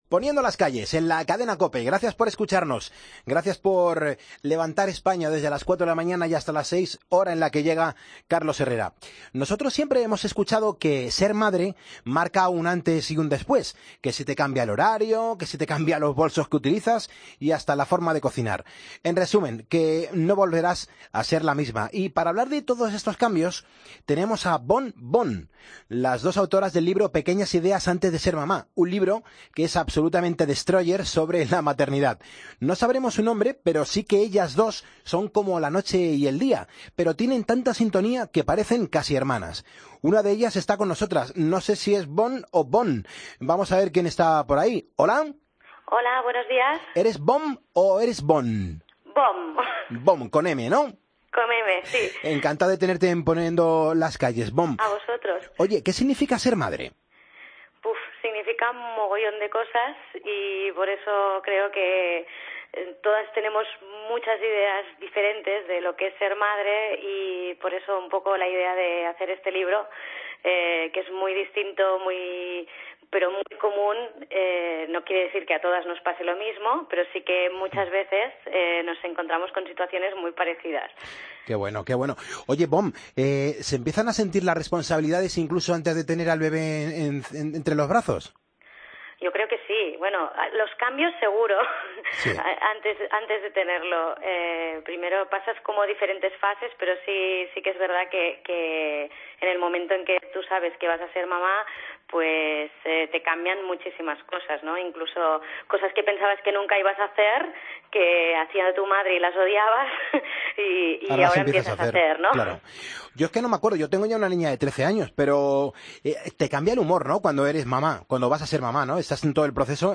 nosotros hablamos con una de ellas.